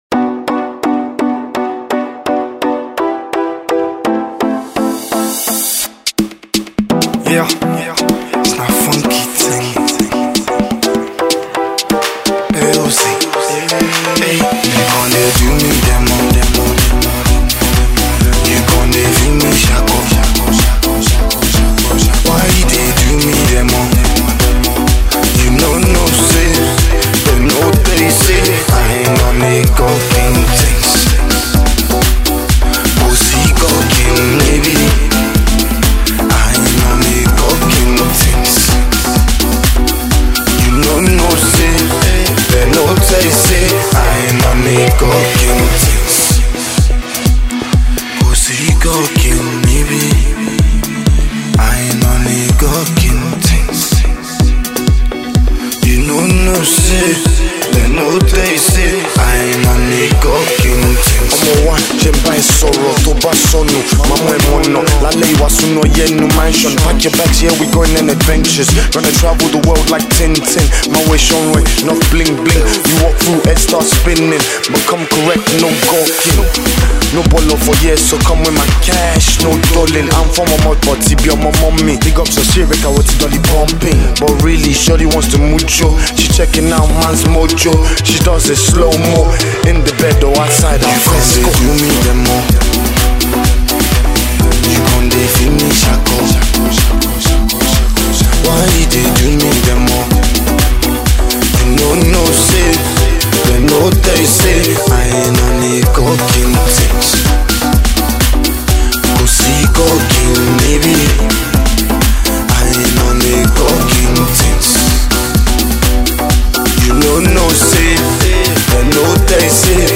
New School House track